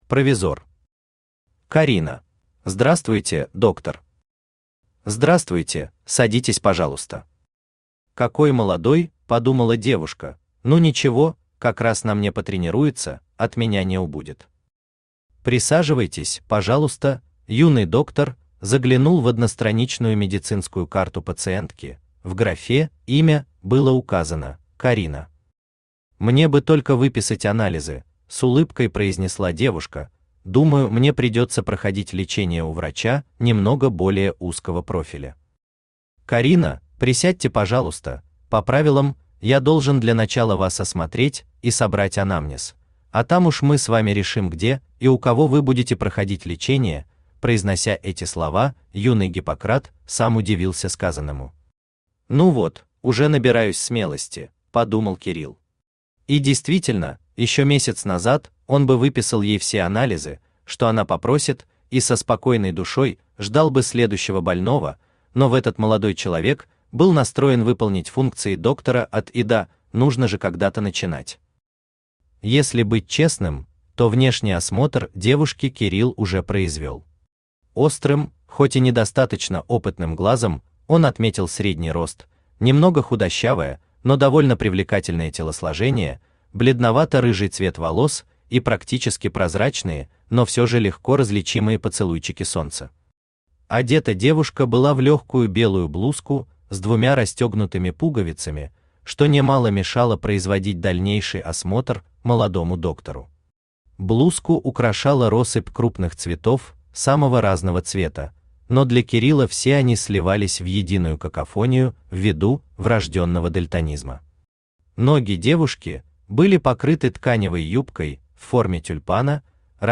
Аудиокнига Карина | Библиотека аудиокниг
Aудиокнига Карина Автор Provizor Читает аудиокнигу Авточтец ЛитРес.